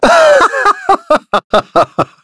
Shakmeh-Vox_Human_Happy5.wav